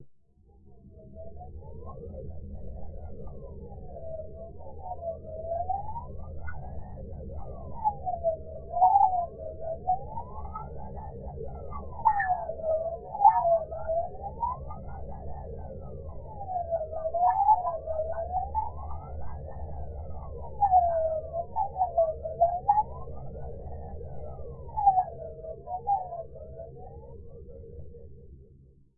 空间哨子
描述：使用各种滤波器，混响和移相器重新处理VST合成声音。
Tag: R everb回响 效果 口哨 过滤器 效果 FX 空间 VST 噪声 音响